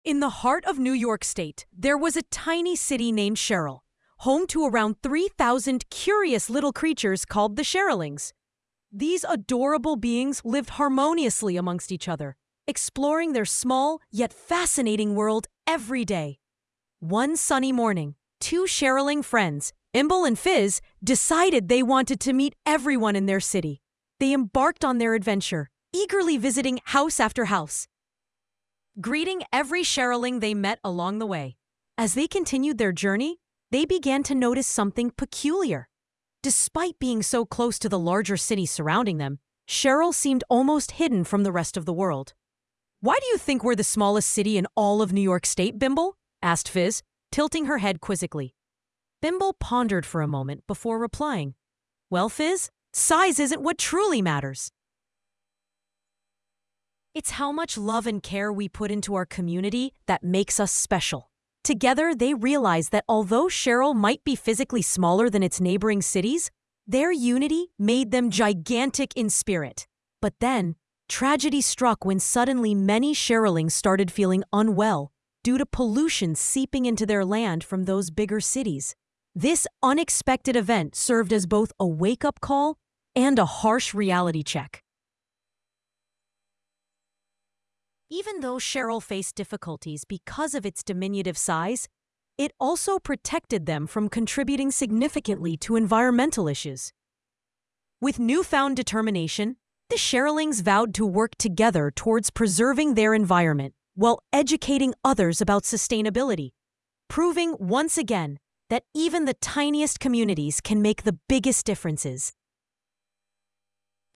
story
tts